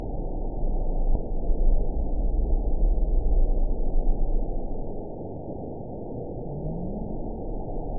event 922756 date 03/26/25 time 02:29:18 GMT (2 months, 3 weeks ago) score 9.46 location TSS-AB10 detected by nrw target species NRW annotations +NRW Spectrogram: Frequency (kHz) vs. Time (s) audio not available .wav